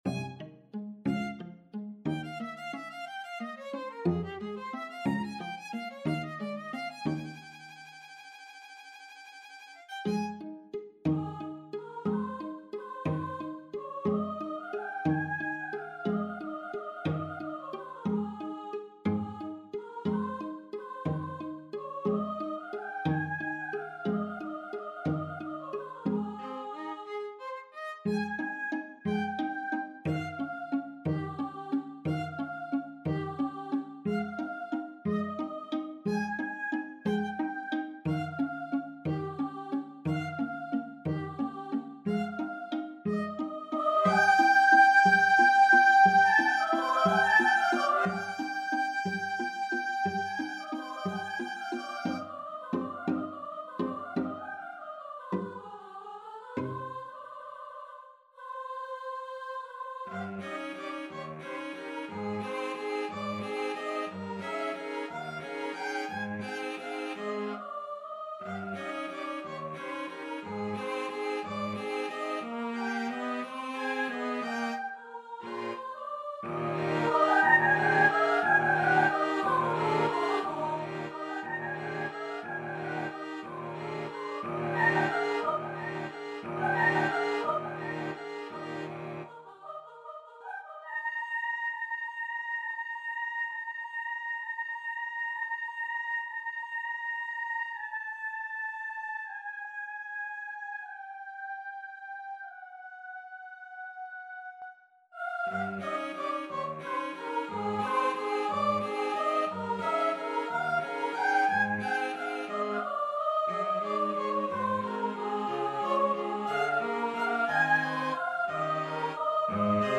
Soprano VoiceViolin 1Violin 2ViolaCello
6/8 (View more 6/8 Music)
Moderato .=60
Classical (View more Classical Voice and Ensemble Music)